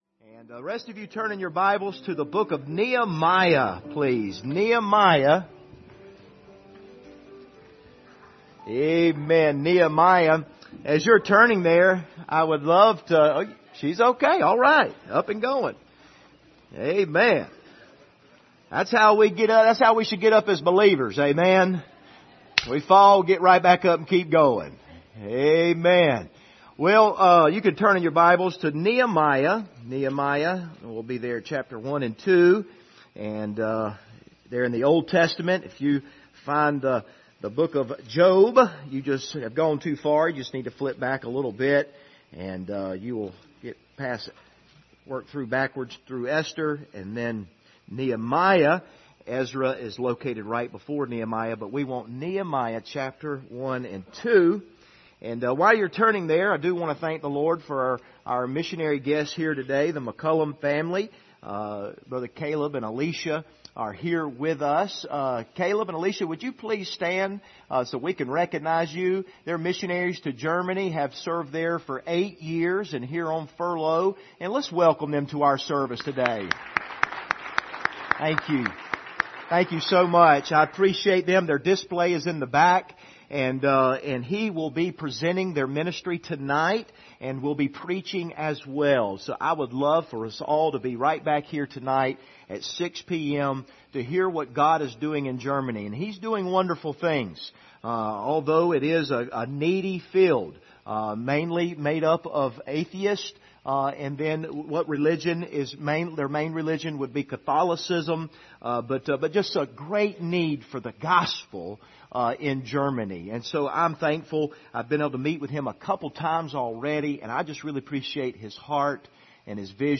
Doing a Great Work Passage: Nehemiah 1:6 - 2:8 Service Type: Sunday Morning View the Video on Facebook « God Help Us!